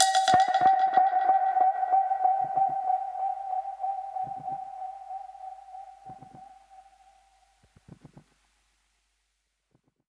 Index of /musicradar/dub-percussion-samples/95bpm
DPFX_PercHit_A_95-01.wav